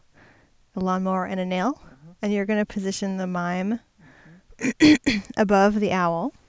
Sometimes at the end of a series of downsteps it is hard to differentiate between L* and !H*.
Look at the word "mime" in this example.